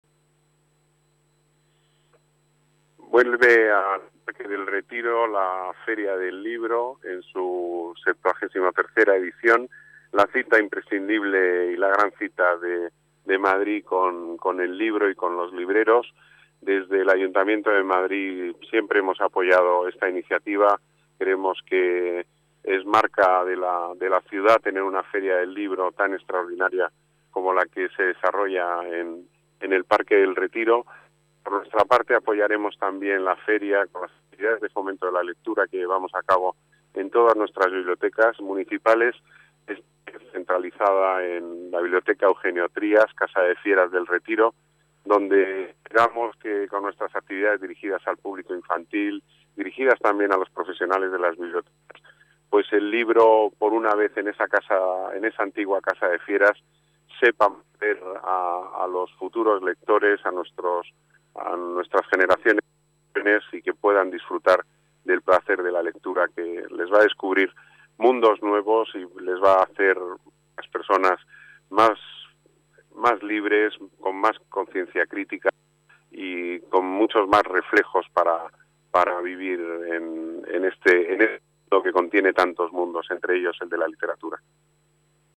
El delegado de Las Artes, Pedro Corral, asiste a la inauguración de la 73 edición que reúne a 508 expositores y 364 casetas
Nueva ventana:Declaraciones del delegado de las Artes, Turismo y Deportes, Pedro Corral